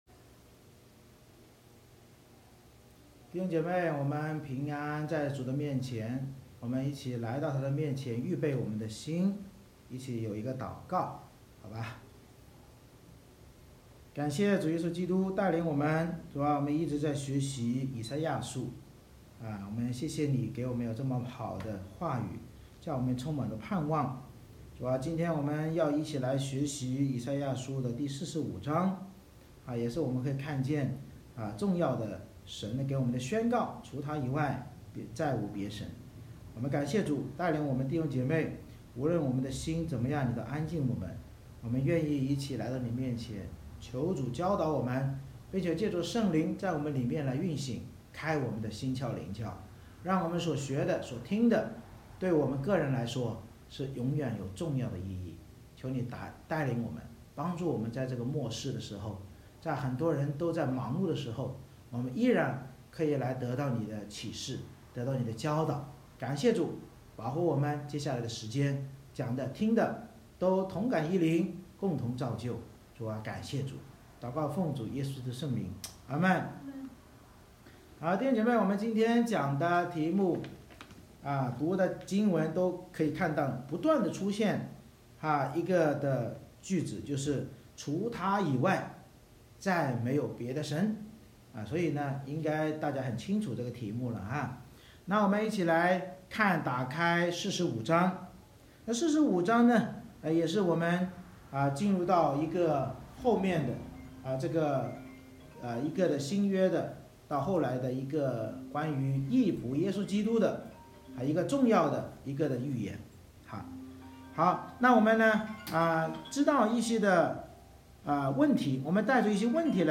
以赛亚书Isaiah45:1-25 Service Type: 主日崇拜 先知预言神必拣选古列王拯救以色列，教导我们不要论断或与神强嘴，因为除了神所拣选的义仆耶稣基督以外别无救主，凡悔改跪拜祂的都必因信称义。